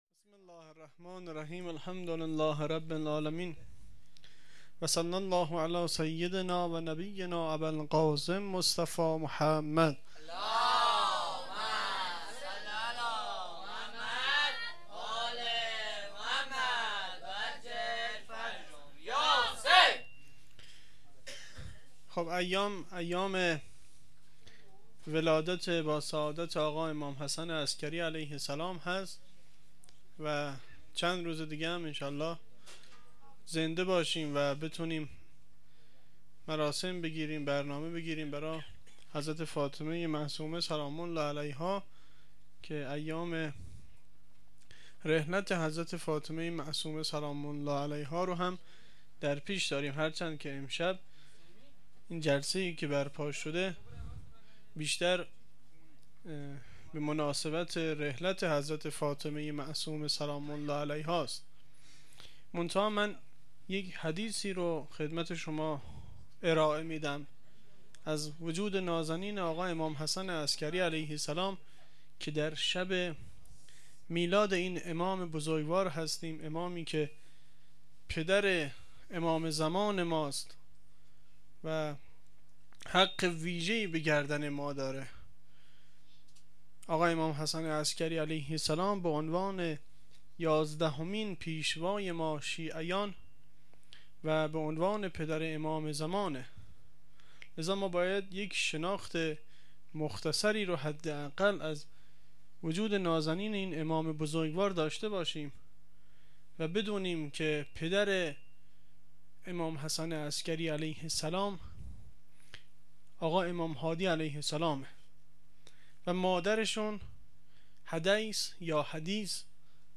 شهر اختیارآباد وفات حضرت معصومه (س) سال 96 هیئت طفلان حضرت مسلم